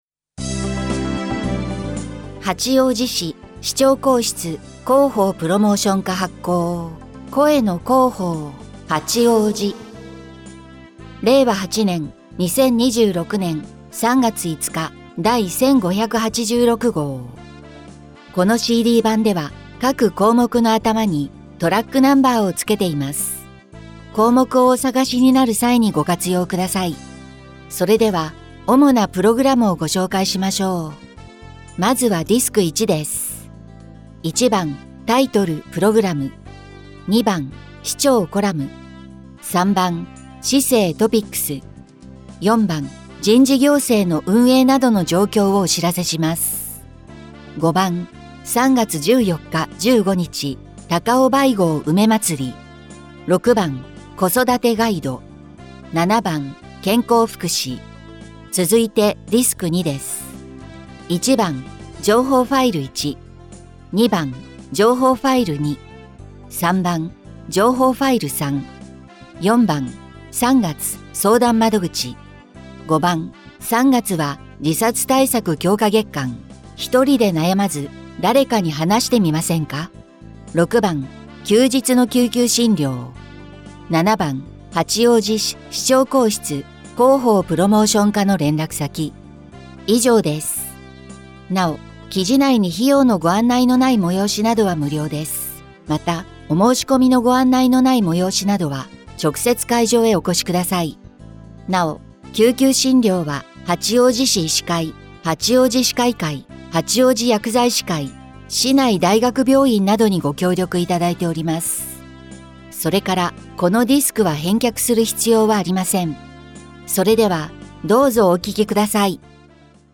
「声の広報」は、視覚障害のある方を対象に「広報はちおうじ」の記事を再編集し、音声にしたものです。